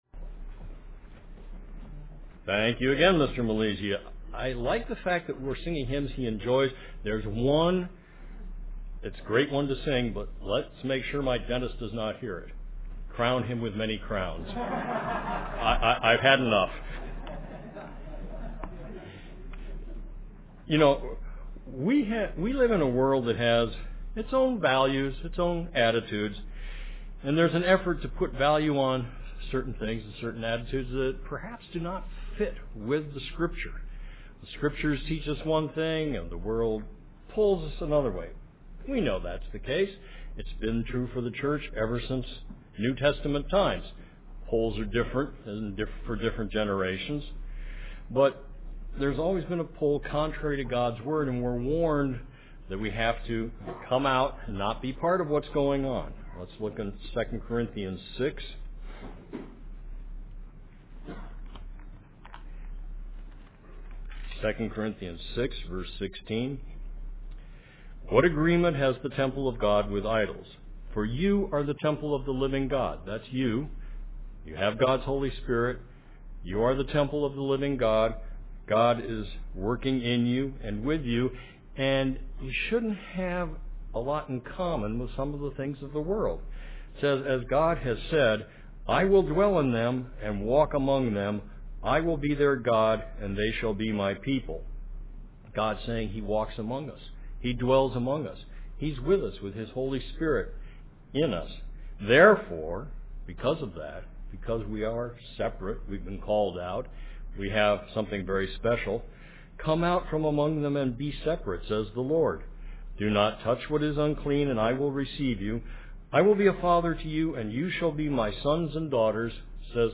Given in San Francisco Bay Area, CA
UCG Sermon Studying the bible?